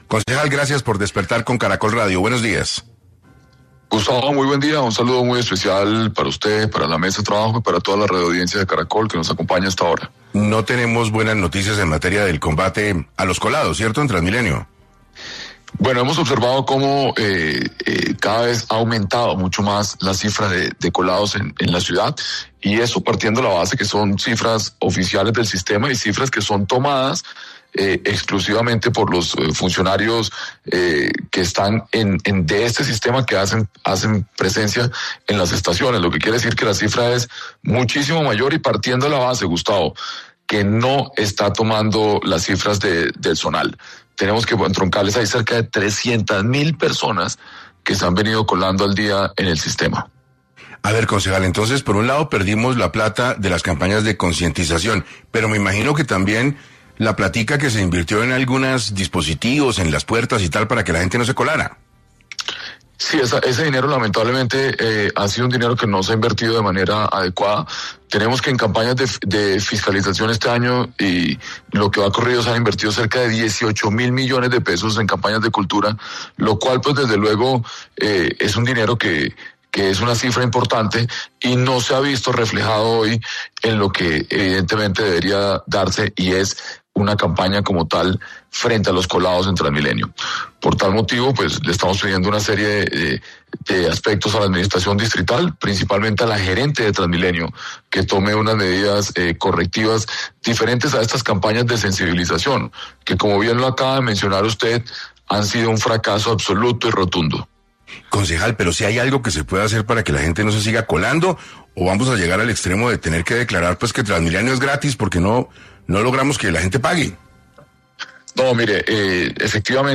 En entrevista con 6AM el concejal, Rolando González, habló de esta situación e indicó que cada día crece el número de colados en el sistema y cabe recordar que no se están sumando las cifras de los colados en el componente zonal.